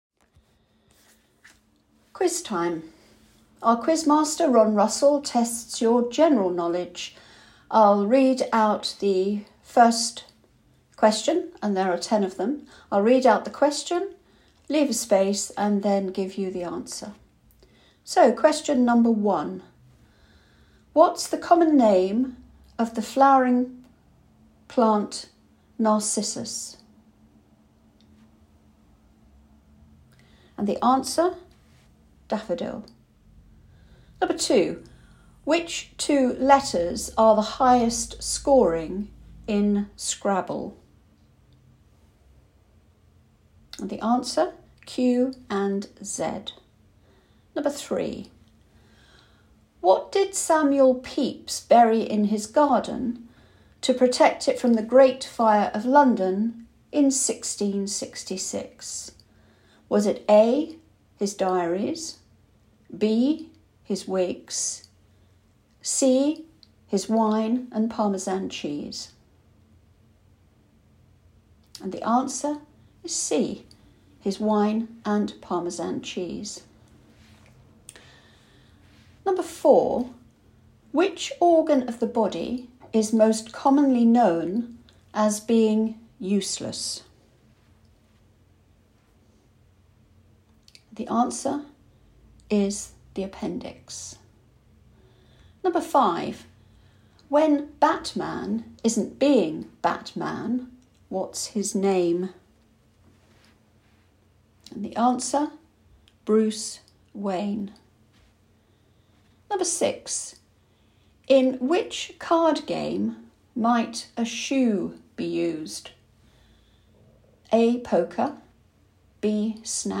Blind Veterans UK Review April 2023: Quiz and answers